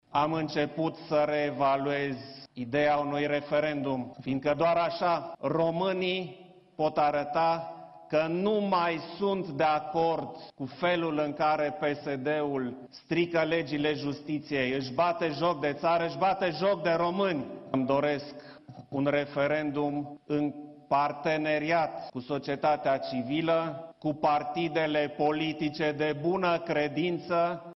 Prezent aseară la Sinaia la Gala Tinerilor Liberali, președintele Klaus Iohannis a lansat noi atacuri la adresa actualului Guvern și a Partidului Social Democrat.
Klaus Iohannis a făcut referire în cadrul discursului pe care l-a susținut și la referendumul pe tema justiției care ar putea fi organizat o dată cu alegerile europarlamentare